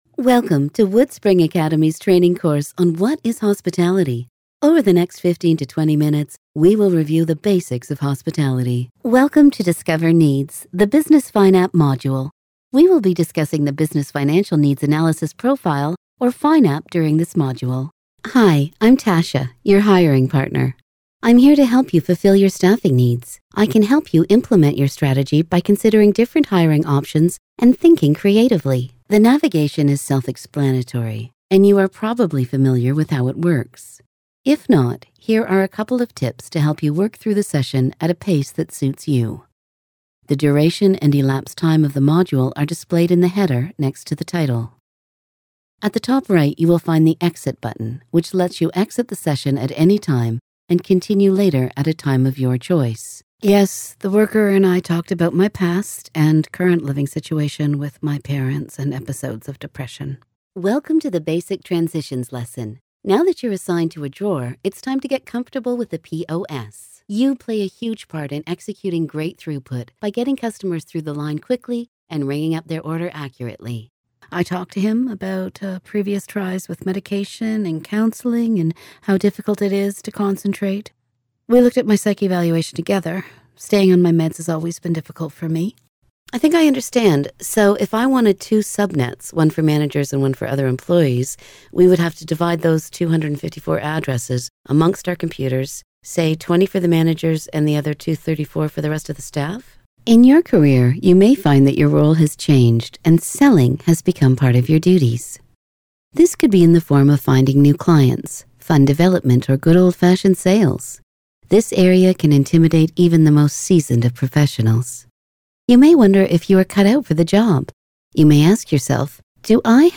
Unique female voice over professional, from sultry and smooth, to wry and conversational
Sprechprobe: eLearning (Muttersprache):
Unique Female Voice Talent